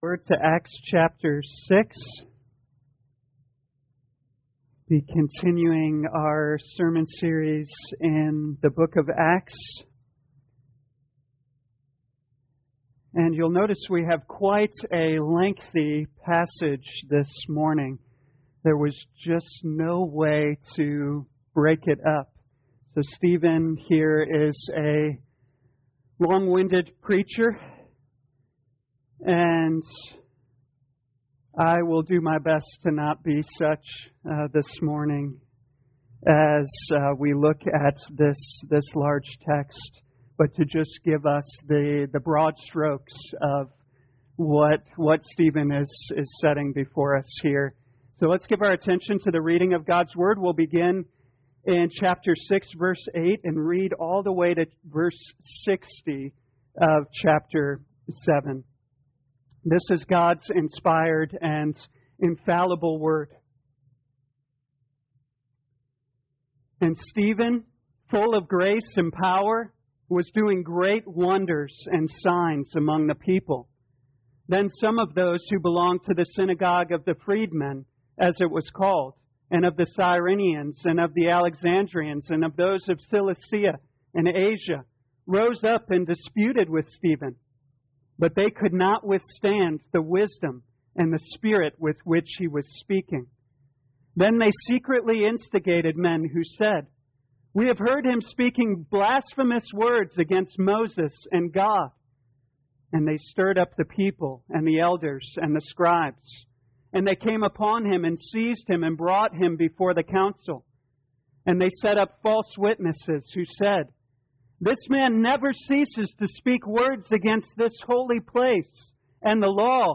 2021 Acts Morning Service Download
You are free to download this sermon for personal use or share this page to Social Media. The Breaking Point Scripture: Acts 6:8